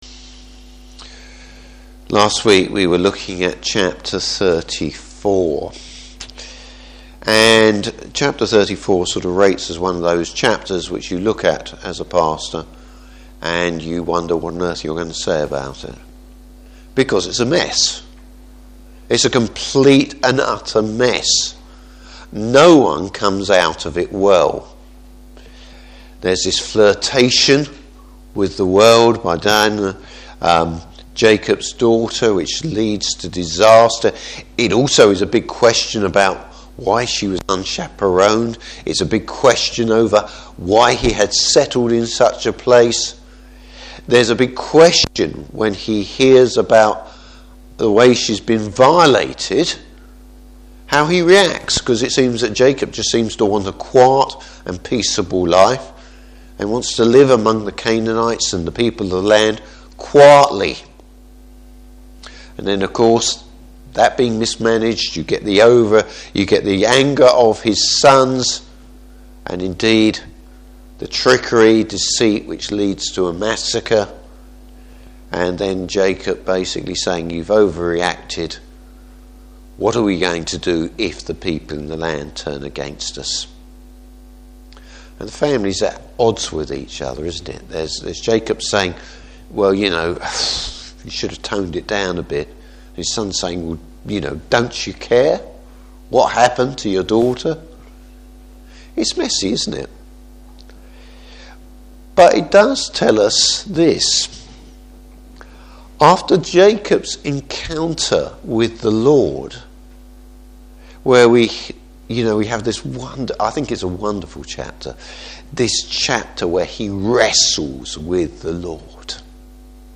Service Type: Evening Service Jacob regains his focus on the Lord.